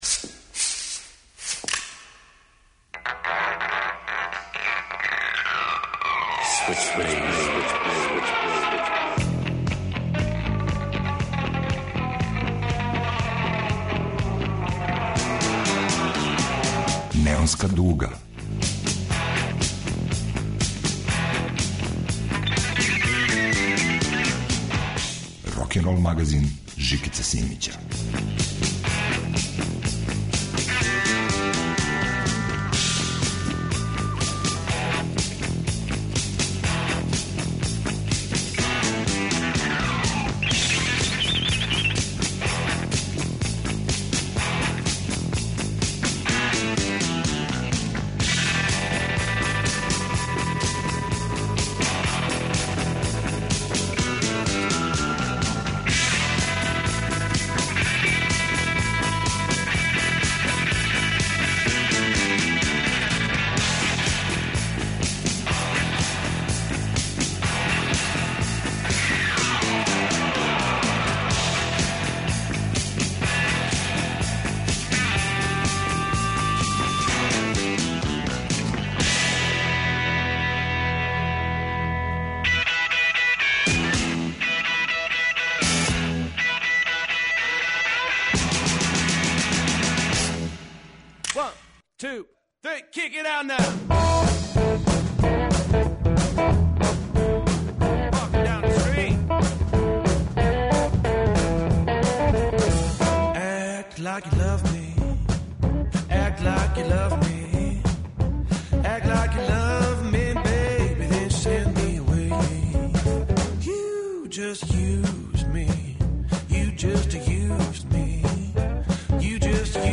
Probudih se ovog jutra: Bluz specijal Neonske duge. Inspirisano Stounsima.